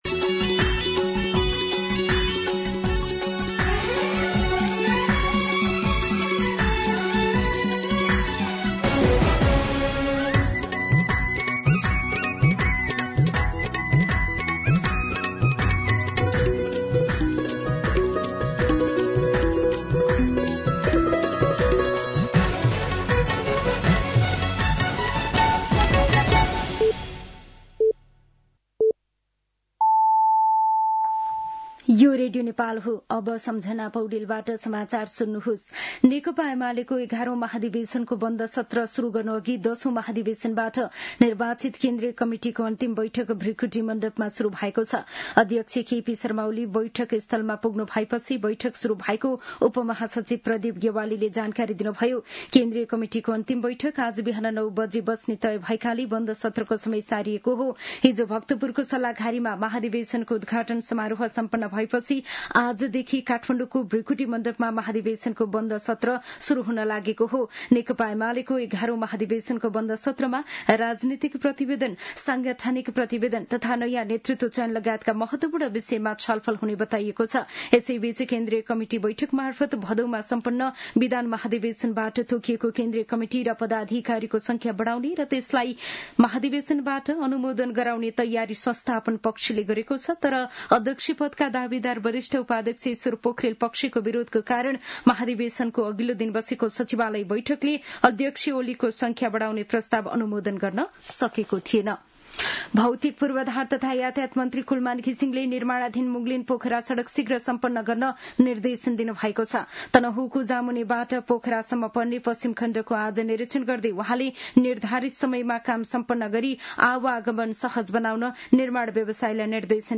दिउँसो १ बजेको नेपाली समाचार : १८ पुष , २०२६
1-pm-News-8-28.mp3